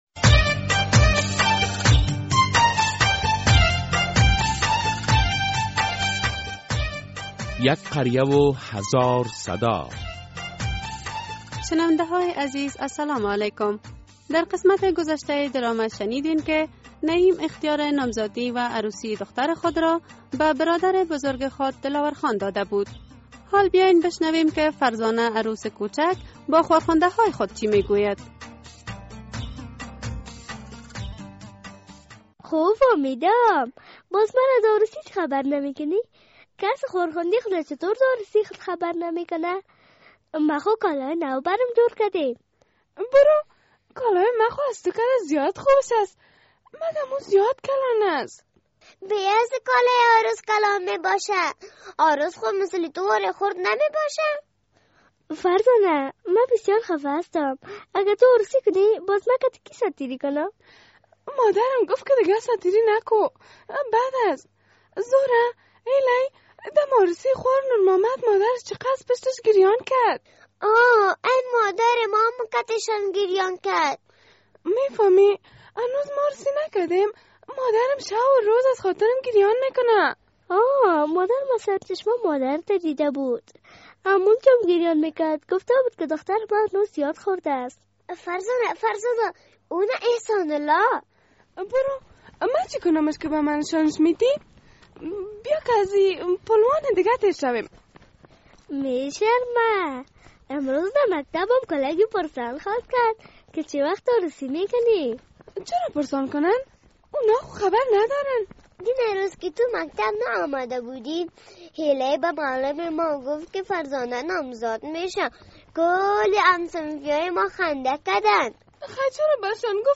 در این درامه که موضوعات مختلف مدنی، دینی، اخلاقی، اجتماعی و حقوقی بیان می‌گردد هر هفته به روز های دوشنبه ساعت ۳:۳۰عصر از رادیو آزادی نشر می گردد...